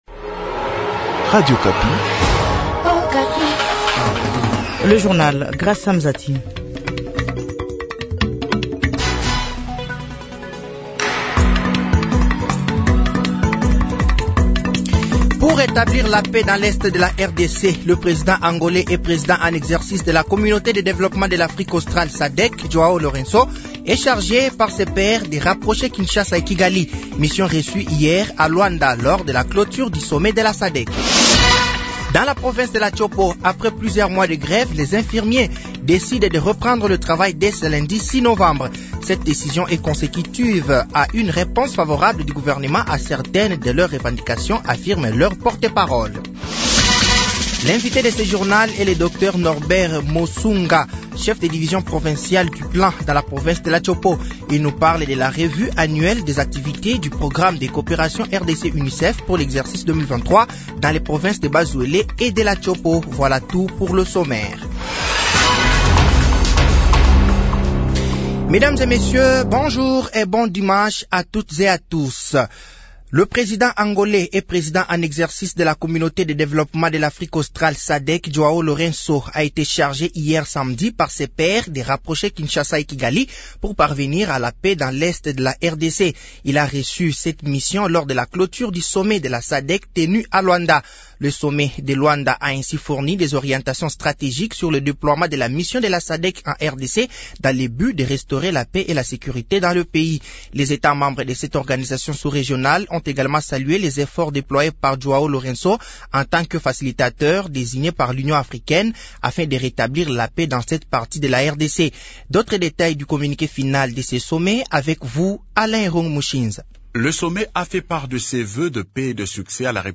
Journal français de 12h de ce dimanche 05 novembre 2023